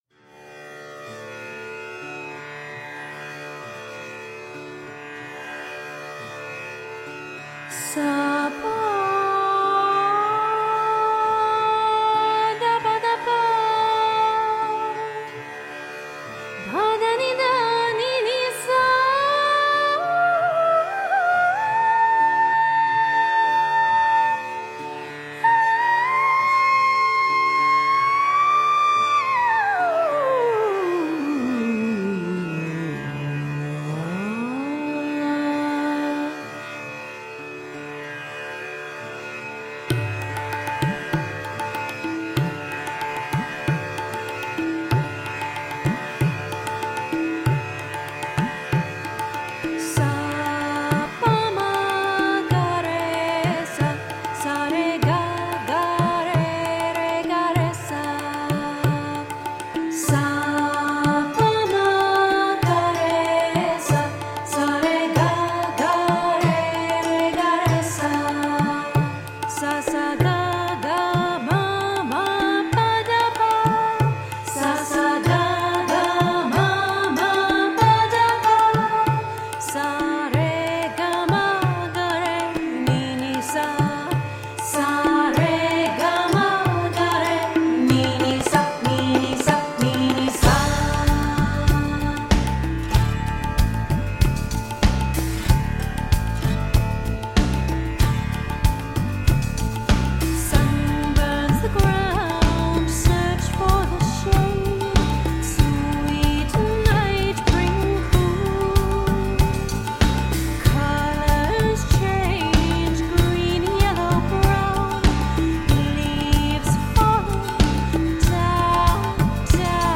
Electro-balkan / indian meets new age..